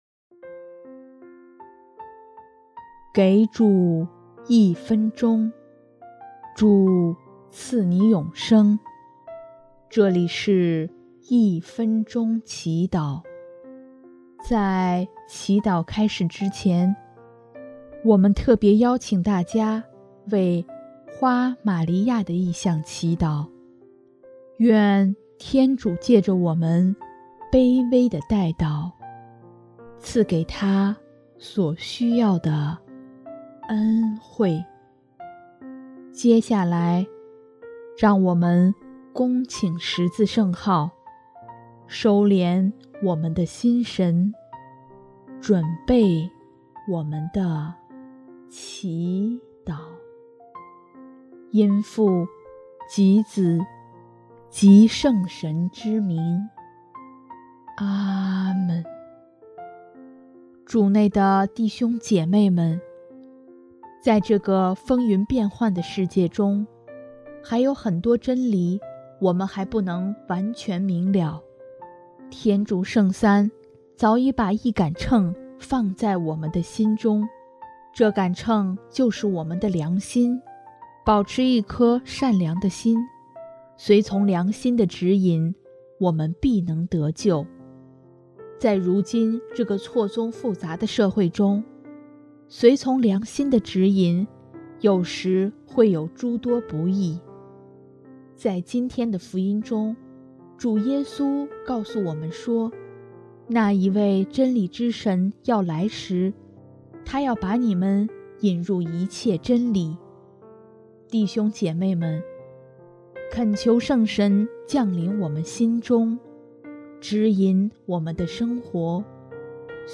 【一分钟祈祷】|5月8日 保持一颗善良的心